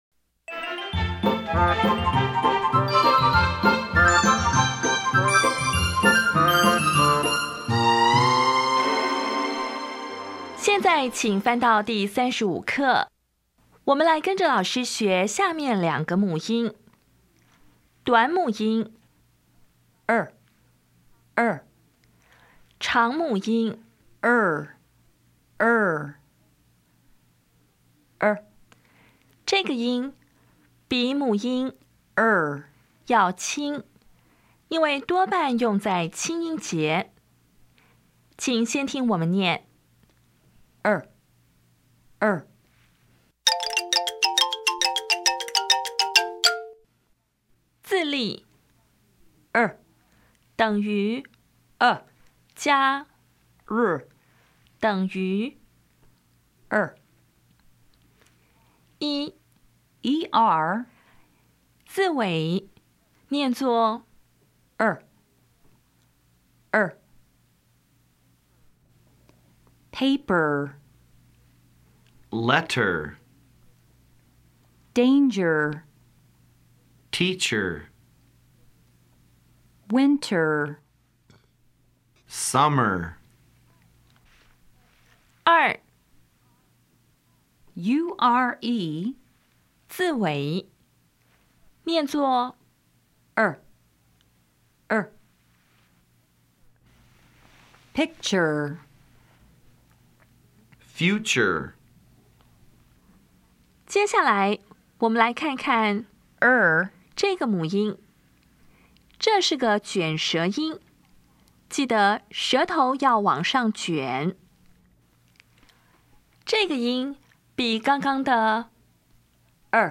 [ɚ] (短) [ɝ](长)
音标讲解第三十五课
[ɚ]=[ə]+ [r]
[ɝ]=[ʌ]+ [r]
*以上er, ear, ir, ur, or重读时通常念[ɝ]的音。